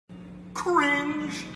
Звуки кринжа